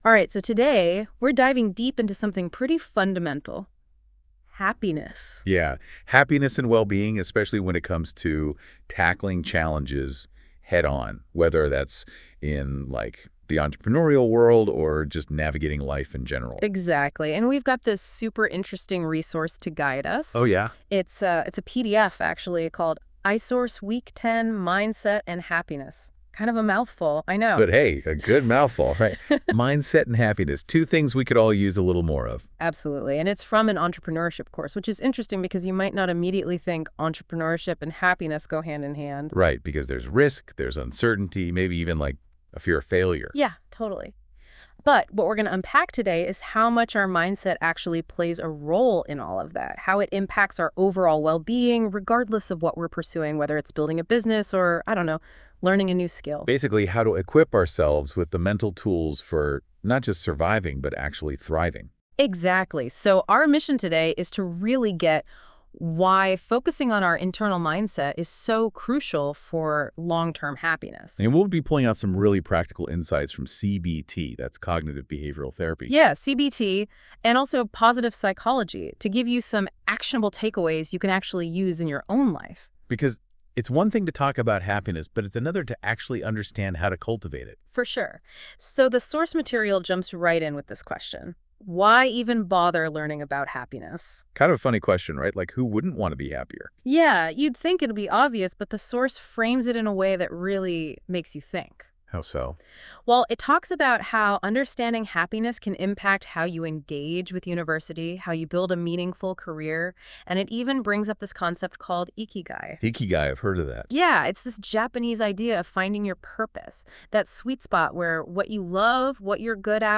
Talk-Show-Week-10-ENT-101-Mindset-and-Happiness.wav